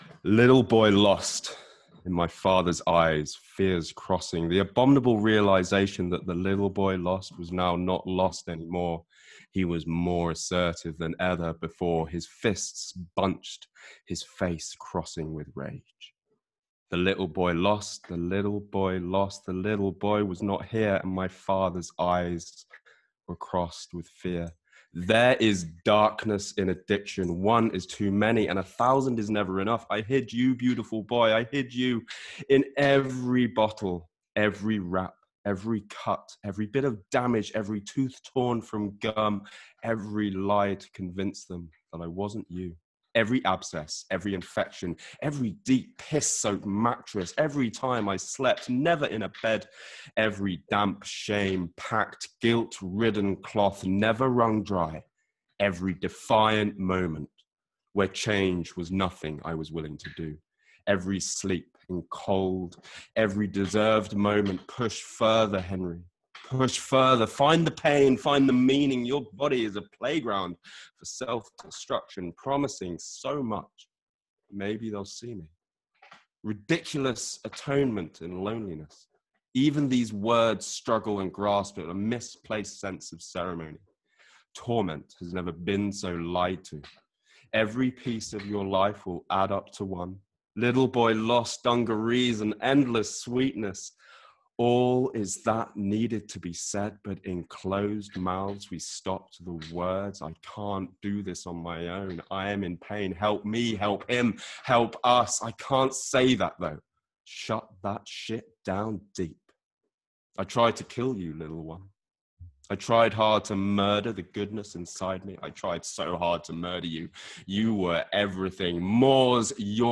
but then blew us all away with his spoken word performance of his untitled poem about finding that fierce 11 year old self who helped him start his journey of recovery.